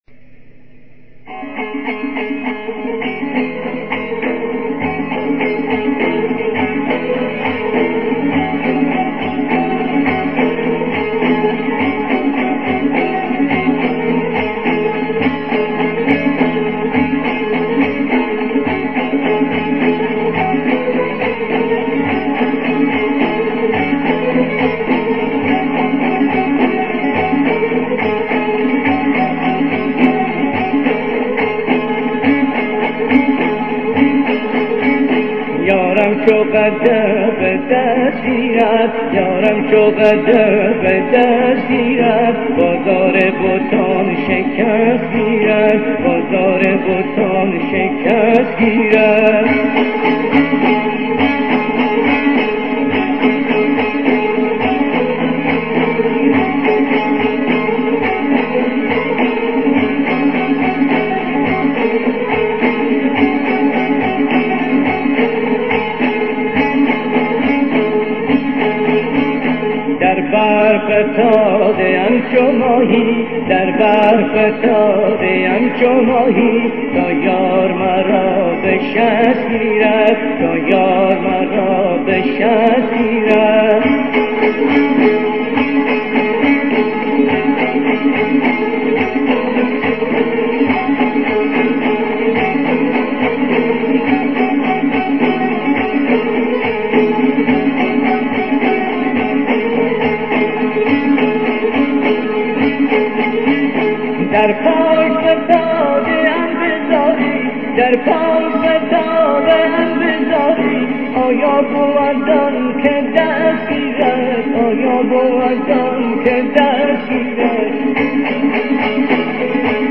Ёрам бо сабки мусиқии хуросониро бишунавед: